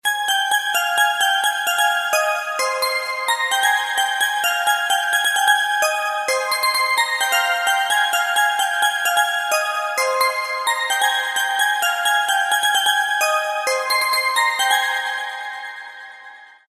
• Качество: 128, Stereo
звонкие
Весёлая мелодия смс